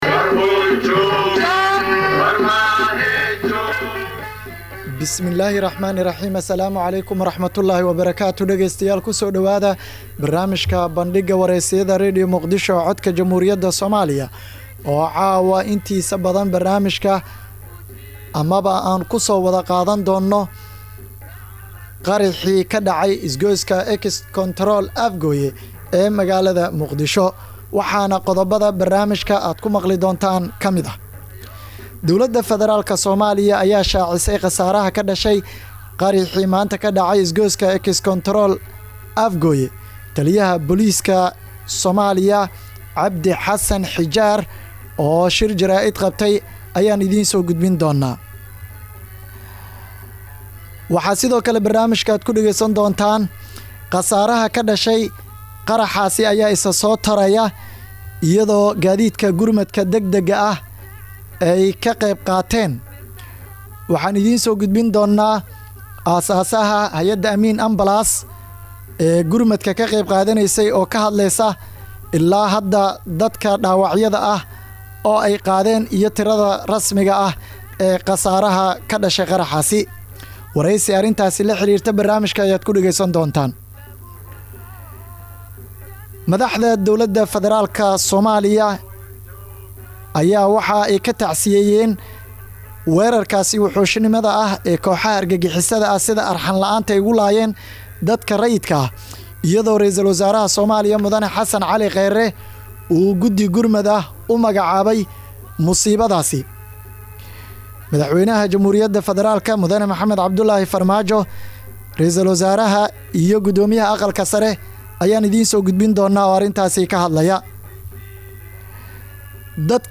Dhageyso: Barnaamijka Bandhigga wareysiyada Radio Muqdisho.